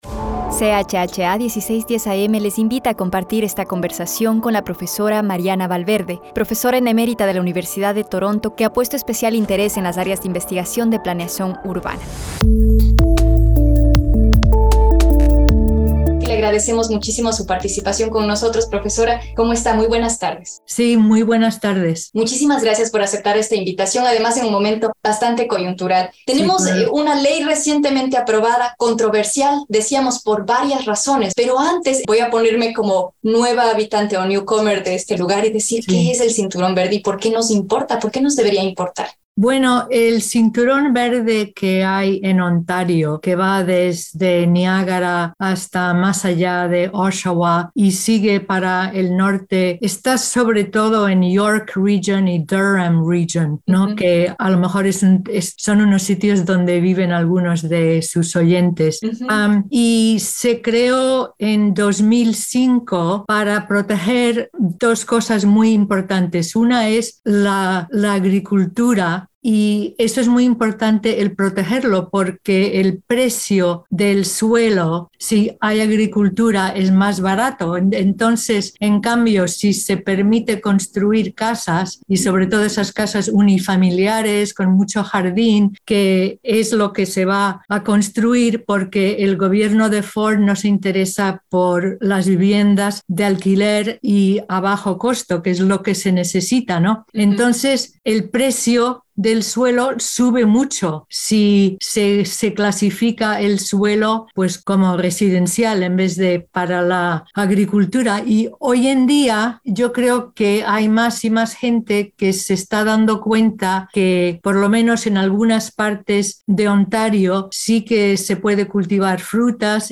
Muchos de estos aspectos los abordamos en CHHA 1610 am